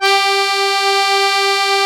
MUSETTE 1 .8.wav